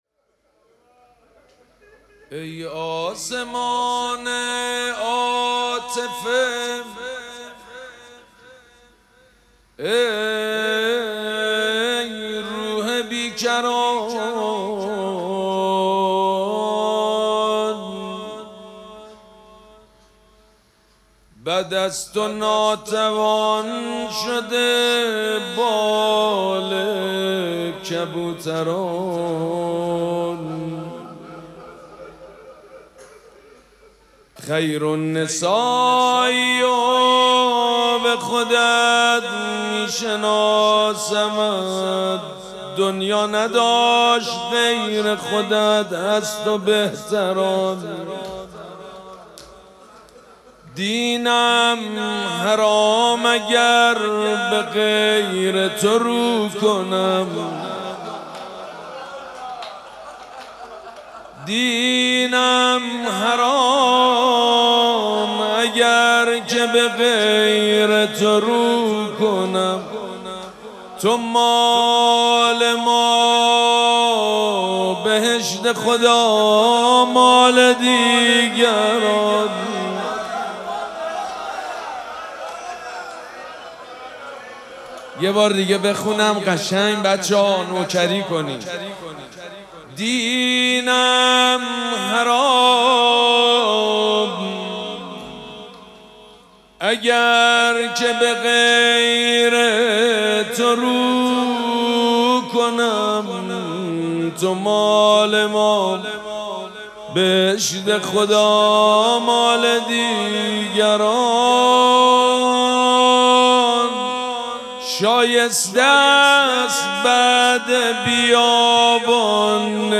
شب دوم مراسم عزاداری دهه دوم فاطمیه ۱۴۴۶
حسینیه ریحانه الحسین سلام الله علیها
روضه